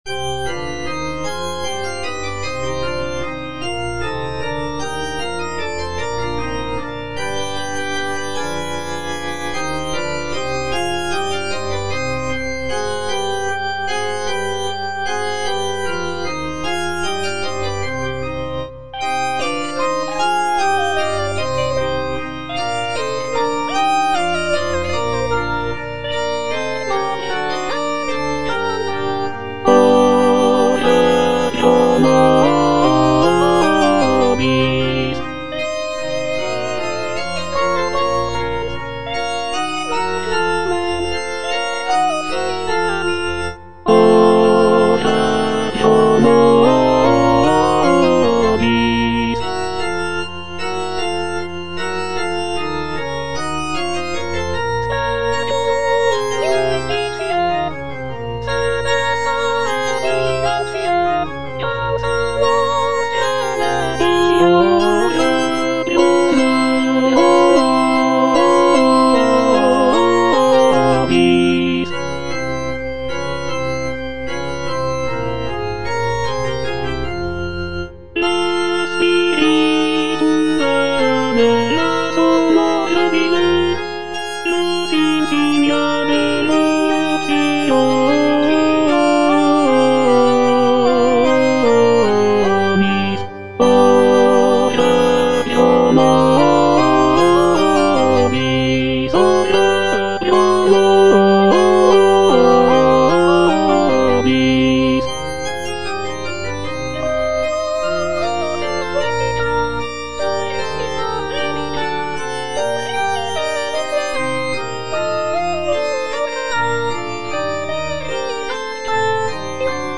F. DURANTE - LITANY OF THE VIRGIN IN F MINOR Virgo prudentissima - Tenor (Emphasised voice and other voices) Ads stop: auto-stop